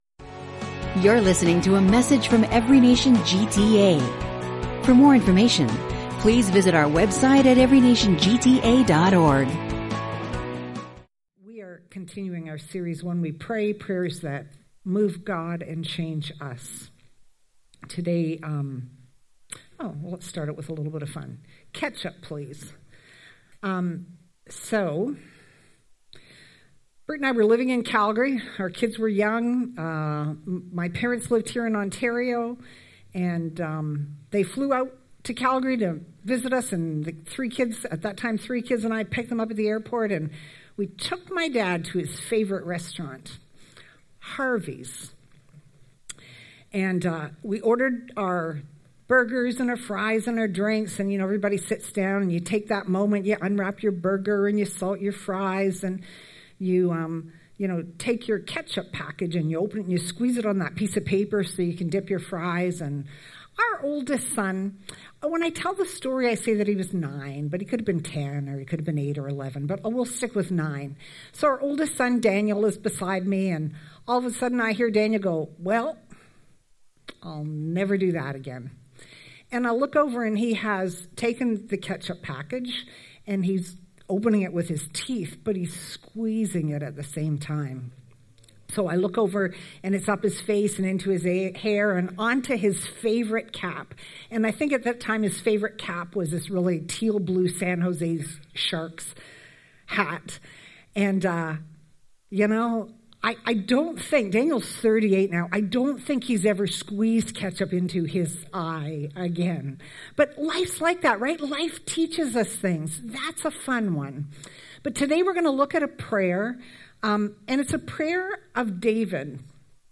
Prayer sermon series